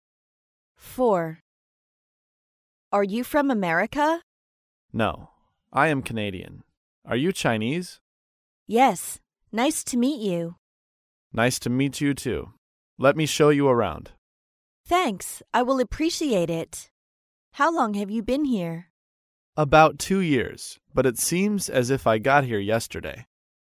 在线英语听力室高频英语口语对话 第4期:异国朋友见面的听力文件下载,《高频英语口语对话》栏目包含了日常生活中经常使用的英语情景对话，是学习英语口语，能够帮助英语爱好者在听英语对话的过程中，积累英语口语习语知识，提高英语听说水平，并通过栏目中的中英文字幕和音频MP3文件，提高英语语感。